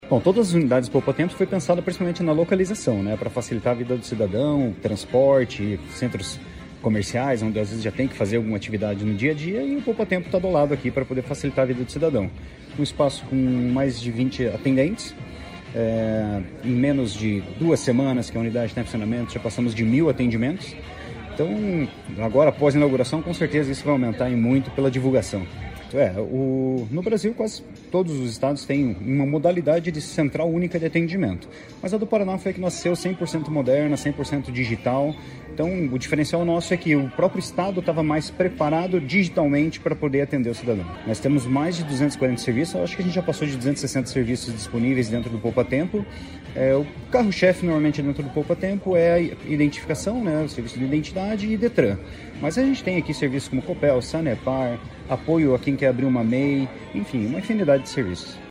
Sonora do superintendente-geral de Governança de Serviços e Dados, Leandro Moura, sobre a unidade do Poupatempo de Apucarana